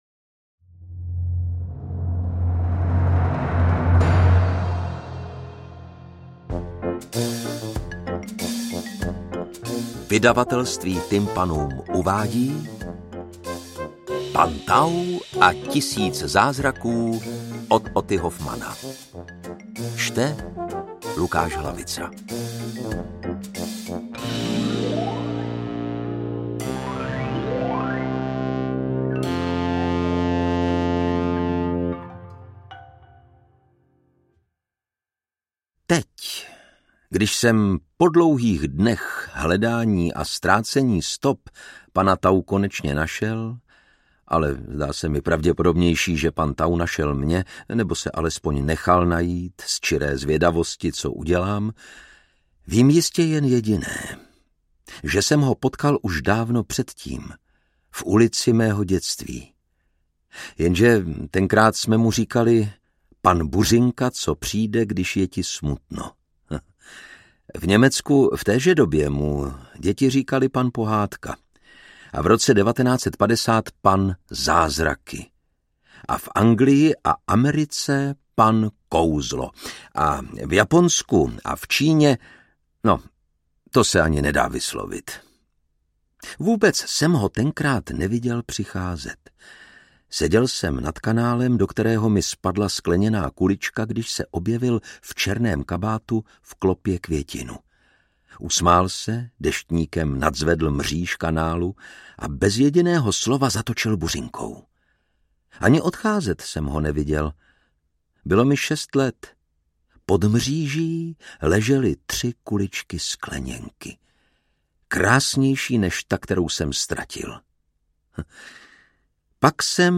AudioKniha ke stažení, 46 x mp3, délka 8 hod. 10 min., velikost 449,1 MB, česky